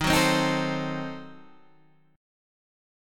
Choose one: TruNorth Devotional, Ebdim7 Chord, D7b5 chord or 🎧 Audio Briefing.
Ebdim7 Chord